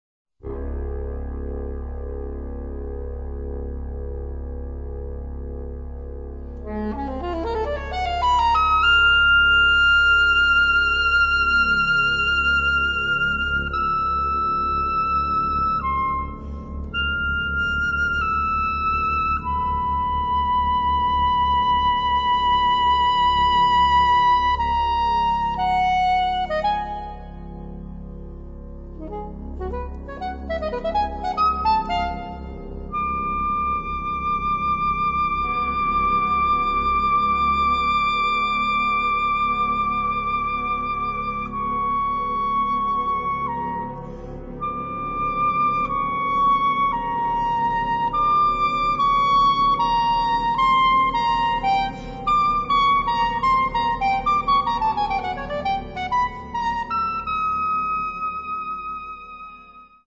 Electronics
Soprano sax